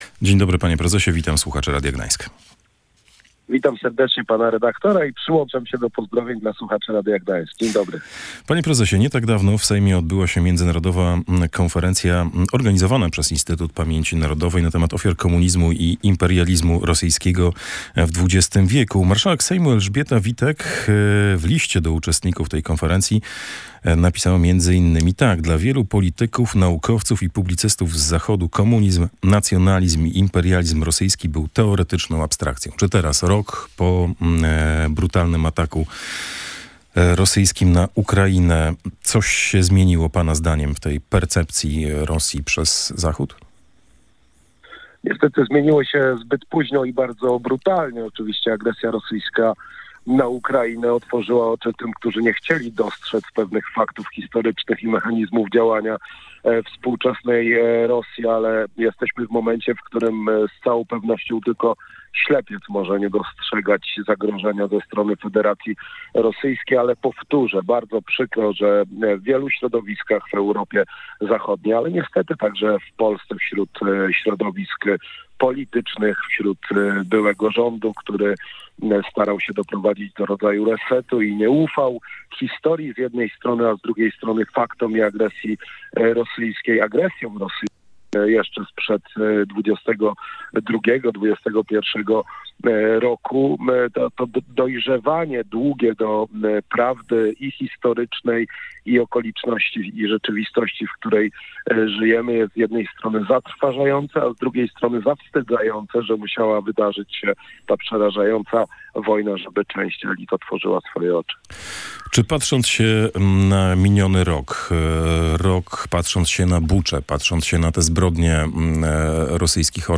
„Gość Dnia Radia Gdańsk” w rozmowie